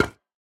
Minecraft Version Minecraft Version snapshot Latest Release | Latest Snapshot snapshot / assets / minecraft / sounds / block / decorated_pot / step4.ogg Compare With Compare With Latest Release | Latest Snapshot
step4.ogg